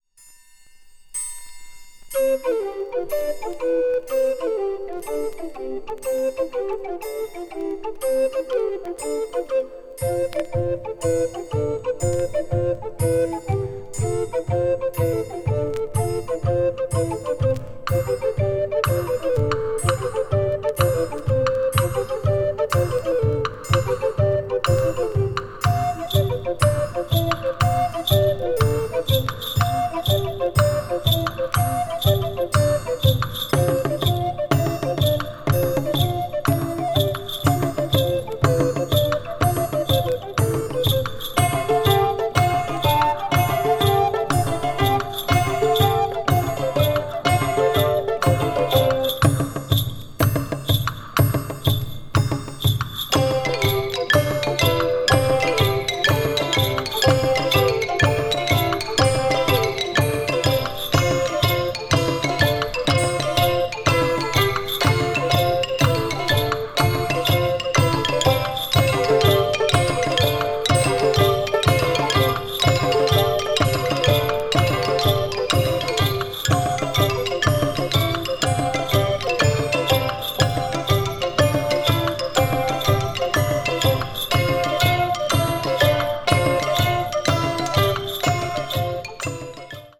media : EX-/EX-(some slightly surface noises.)